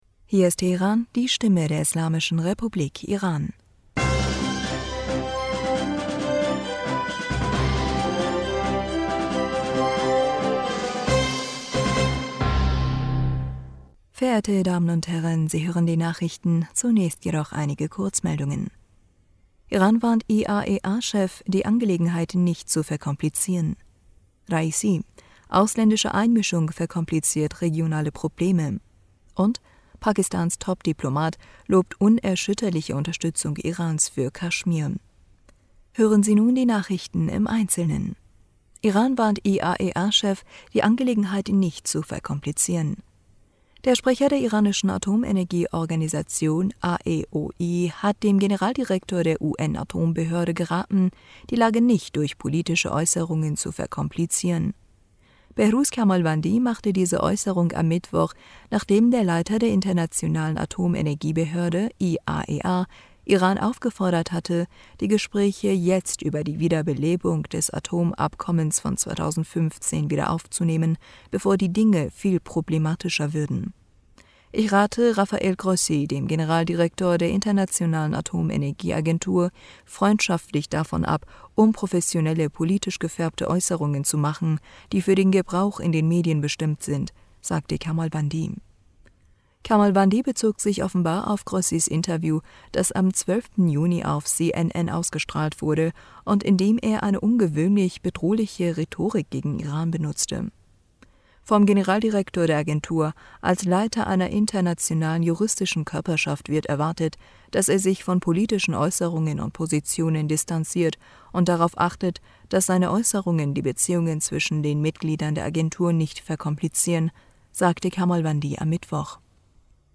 Nachrichten vom 16. Juni 2022